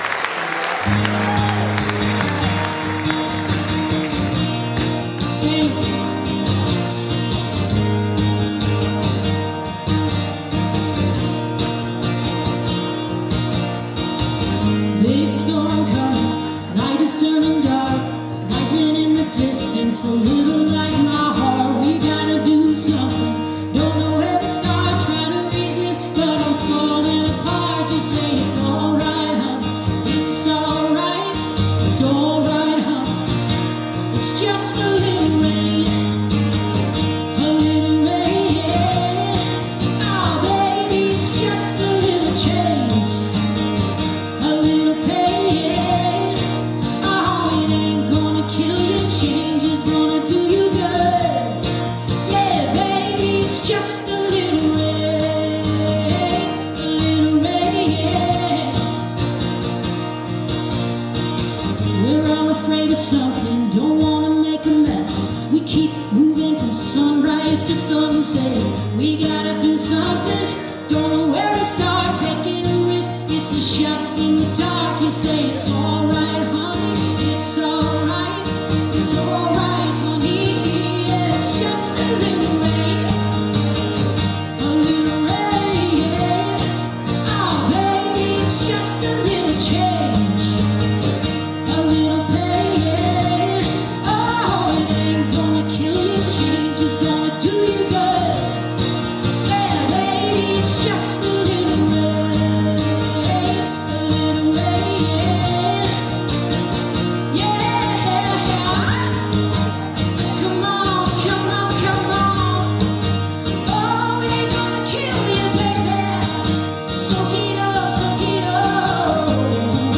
(band show)
(captured from a web stream)